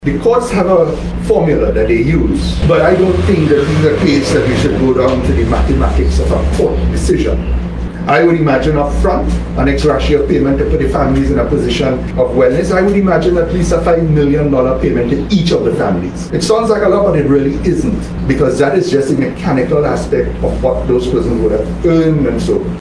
At a media conference today Mr Ramadhar was asked how much payout to the families he is seeking.